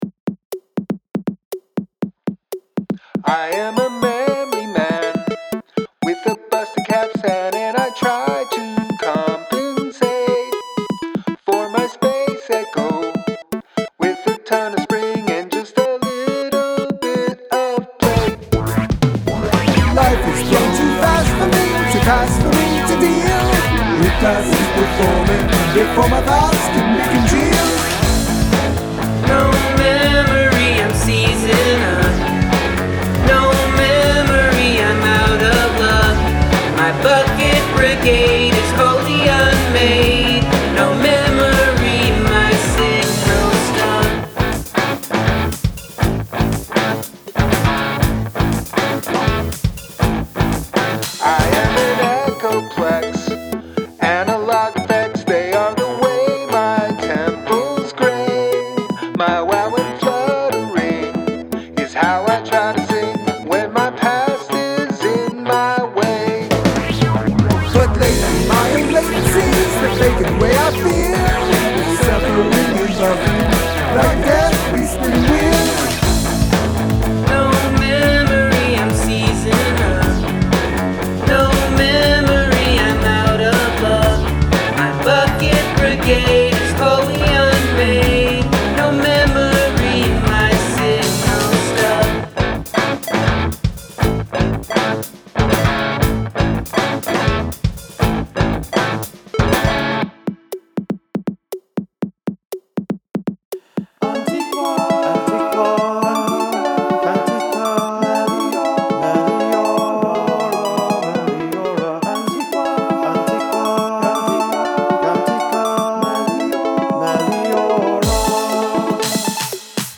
Elements of chiptune/ computer sounds